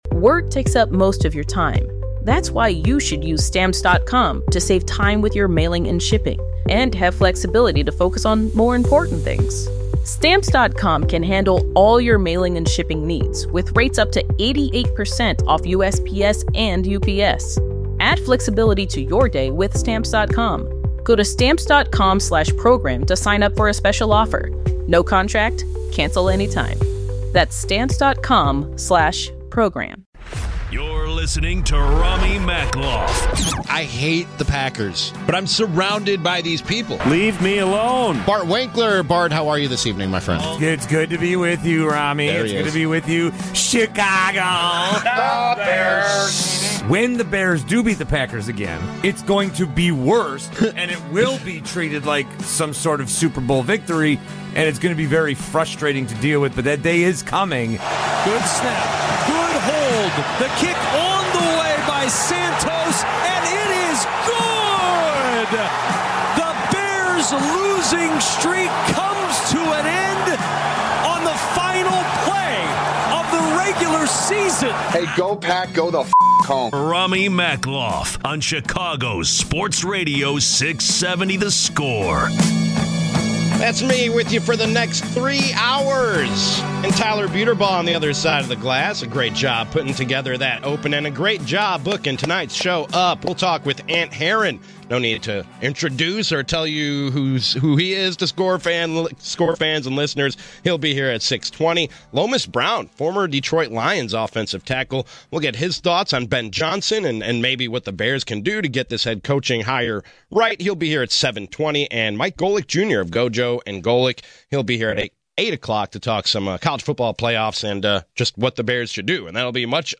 670 The Score personalities react to the latest Chicago sports news and storylines.